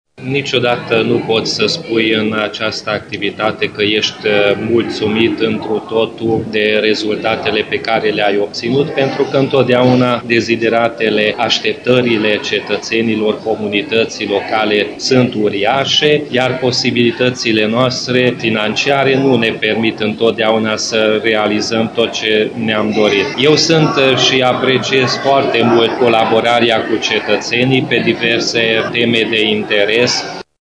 Viceprimarul UDMR Peti Andras a arătat că la baza neîmplinirii unor deziderate au stat problemele finaciare: